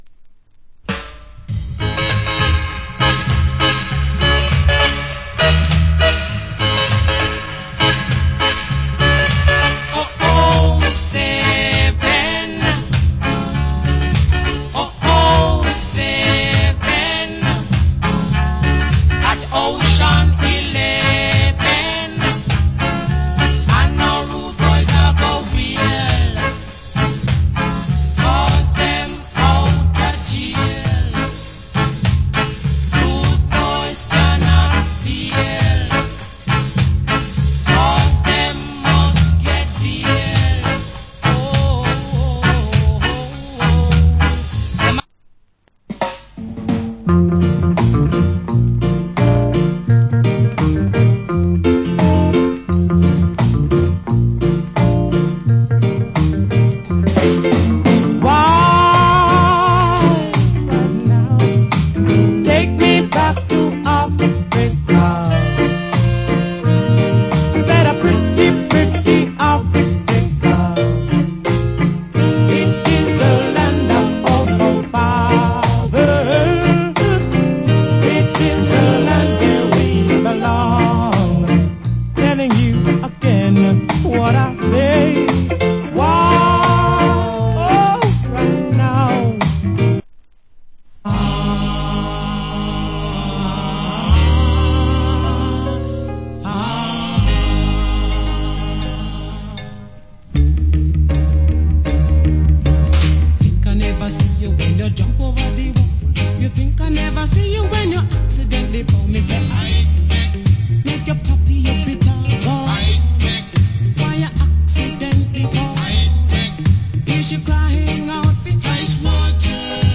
Great Ska, Rock Steady & Reggae.